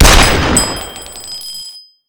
shell.wav